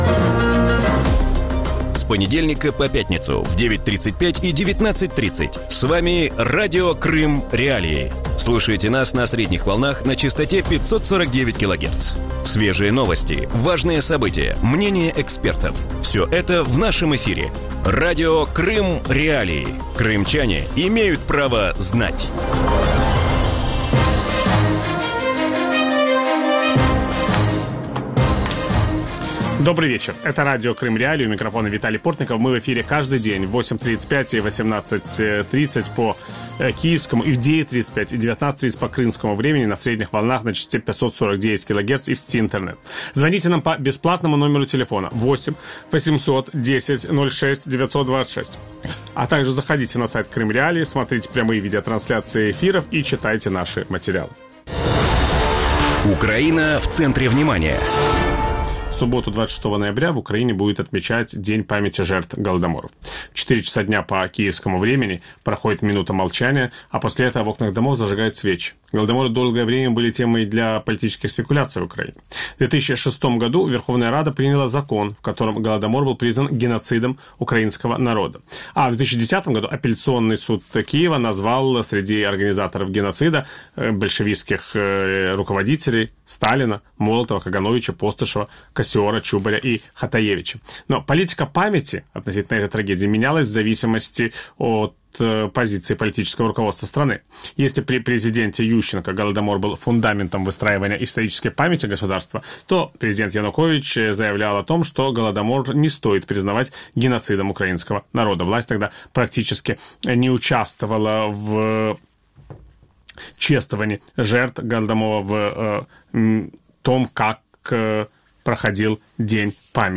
В вечернем эфире Радио Крым.Реалии говорят о Дне памяти жертв Голодомора в Украине. Как Голодомор изменил в свое время Украину и Крым, почему многие не могут признать преступления советского режима и какой должна быть политика памяти этой трагедии?
Ведущий: Виталий Портников.